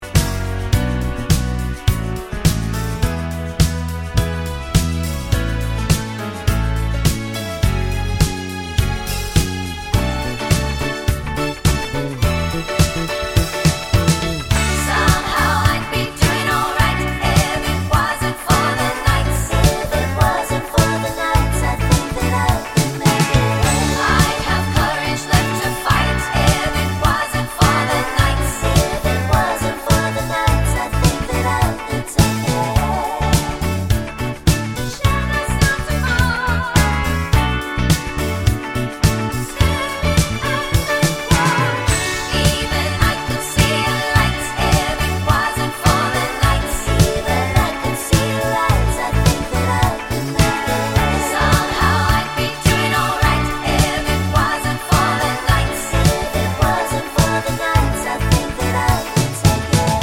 For Solo Singer Pop (1970s) 4:38 Buy £1.50